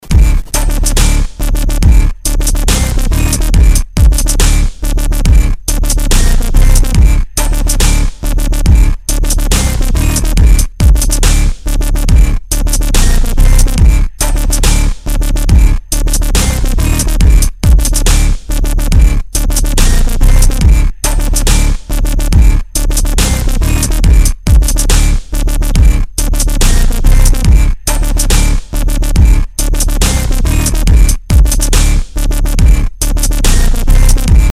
• Качество: 320, Stereo
жесткие
мощные
без слов
Trap
минус
Самодельная инструменталка